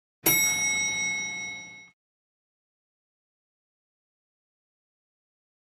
Elevator Bell Dings Loud, Close Perspective.